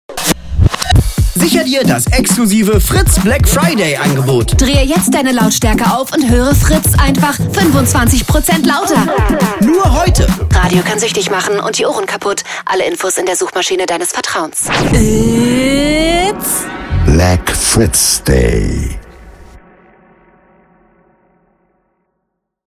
Black Fritzday - Lauter | Fritz Sound Meme Jingle